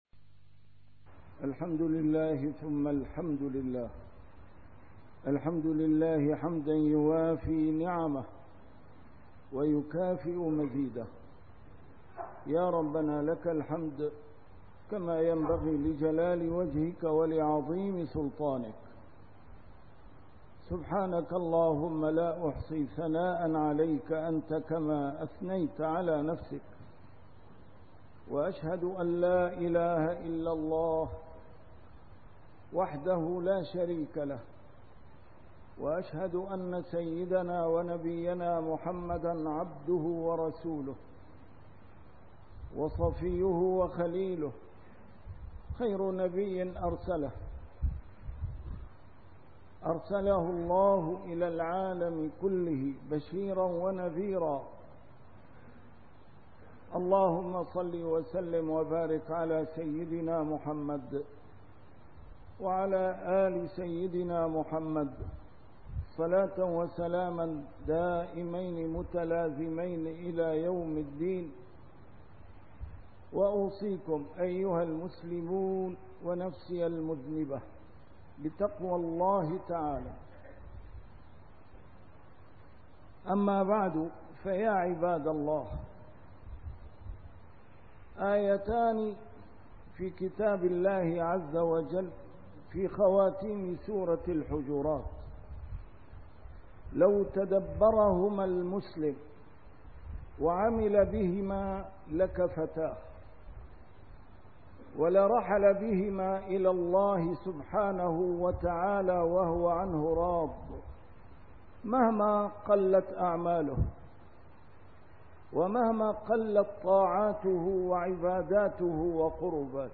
A MARTYR SCHOLAR: IMAM MUHAMMAD SAEED RAMADAN AL-BOUTI - الخطب - لا يضركم من ضل إذا اهتديتم